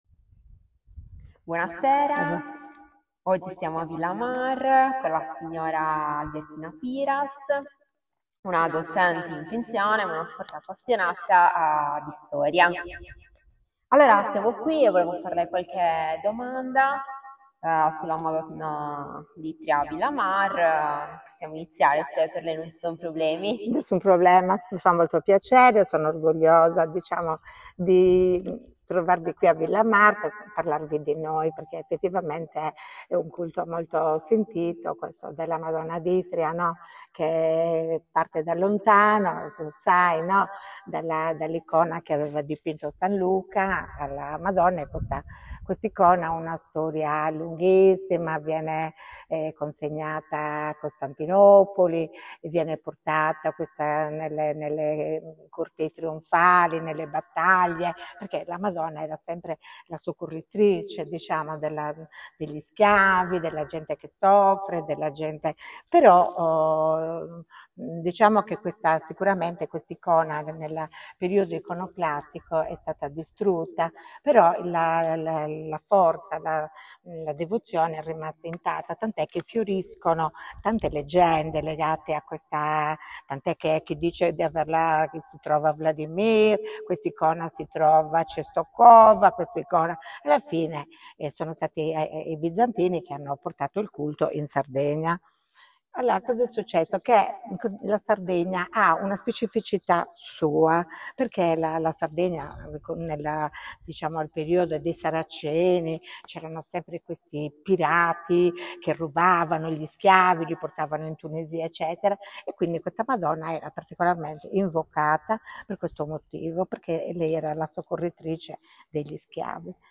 Apparecchiatura di registrazione Microfono e cellulare
Oral History